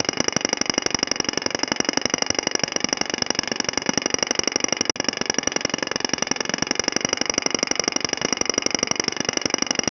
Loopable sound of tarpaulin crank being turned
loopable-sound-of-tarpaul-m4mqv5lv.wav